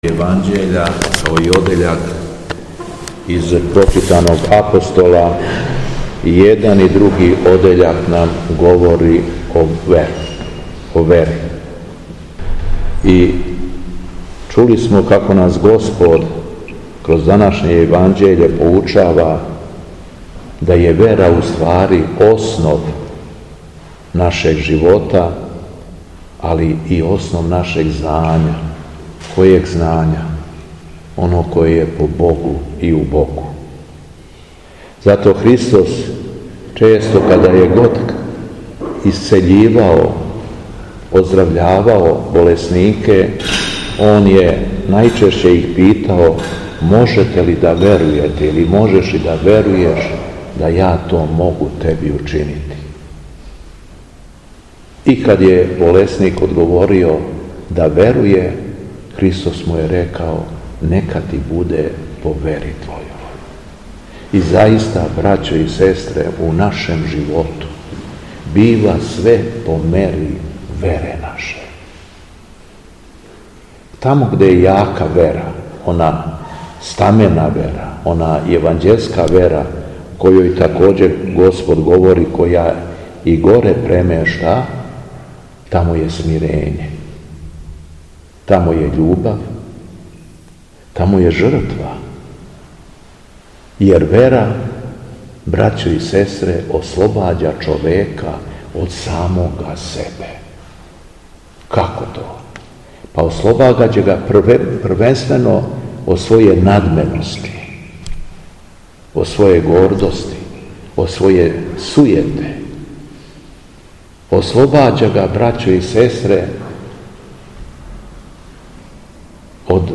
Беседа Његовог Високопреосвештенства Митрополита шумадијског г. Јована
Дана 1. октобра лета Господњег 2025. Његово високопреосвештенство Митрополит шумадијски Господин Јован началствовао је свештеним евхаристијским сабрањем у крагујевачком насељу Бресница у храму Светог Јоаникија Девичког.